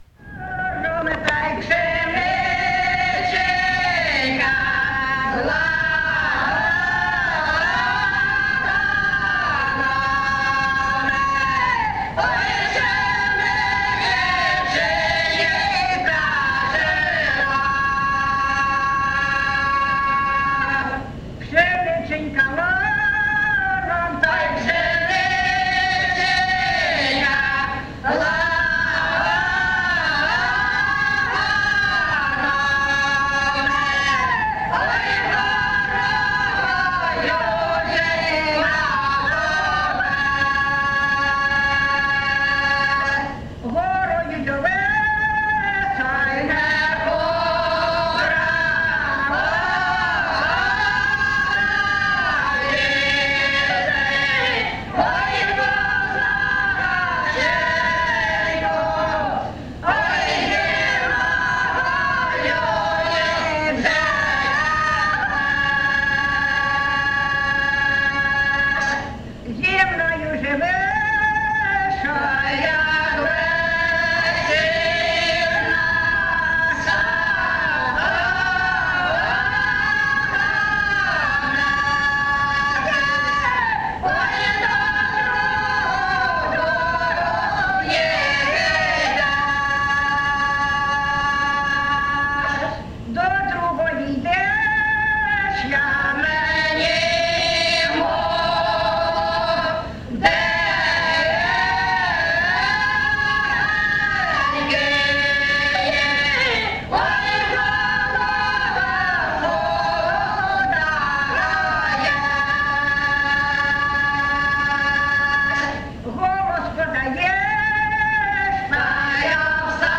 Recording locationOcheretove, Valkivskyi District, Kharkiv obl., Ukraine, Sloboda Ukraine